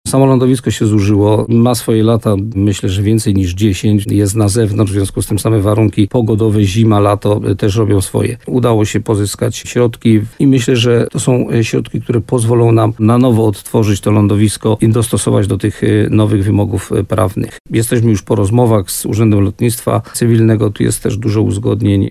Gość programu Słowo za Słowo na antenie radia RDN Nowy Sącz podkreślał, że prace pozwolą na przystosowanie placu do obowiązujących przepisów.